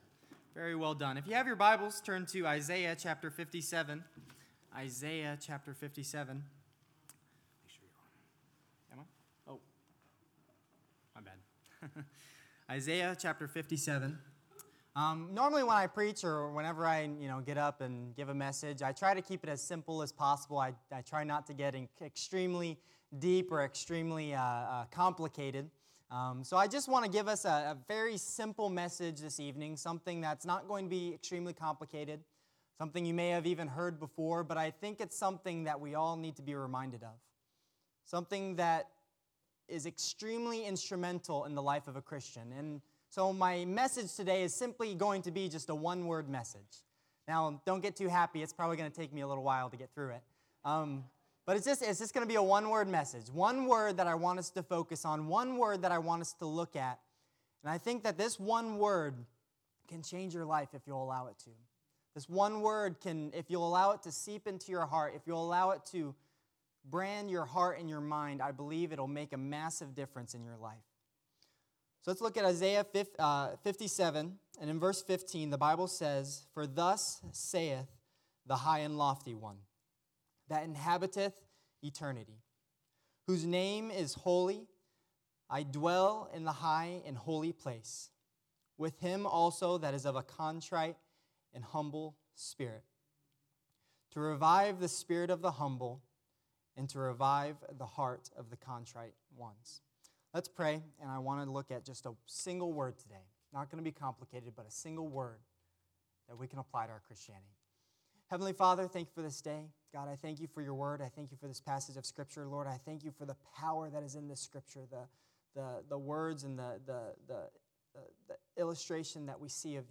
Passage: Isaiah 57:15 Service Type: Sunday Evening « The Indwelling of the Holy Spirit